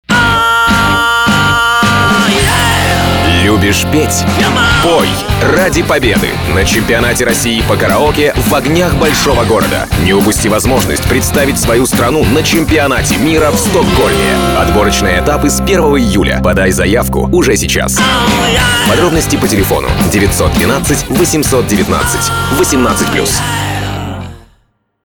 АУДИО-РОЛИК до 30 секунд, 1 день